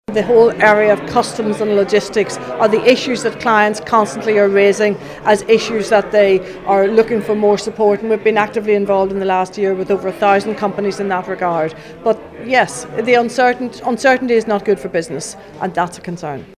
Julie Sinnamon, Chief Executive of Enterprise Ireland, says businesses here are worried by Brexit uncertainty…………….